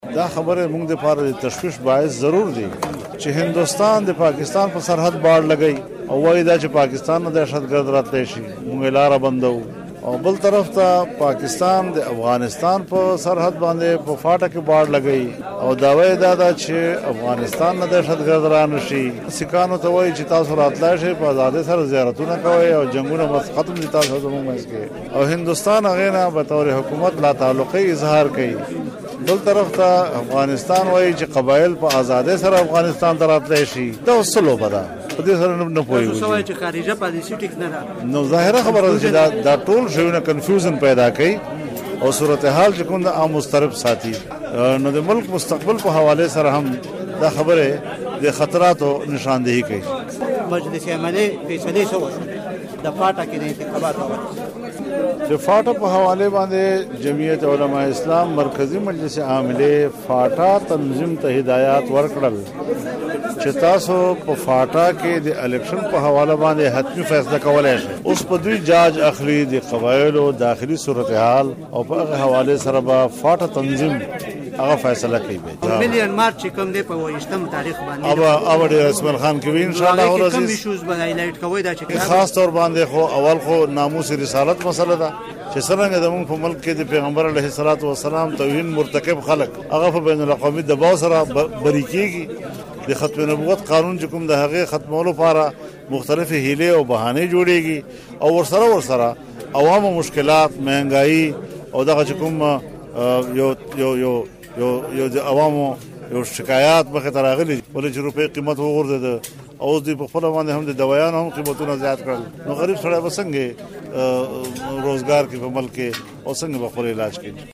د جمعیت العلماء اسلام (ف) مشر مولانا فضل الرحمن مرکه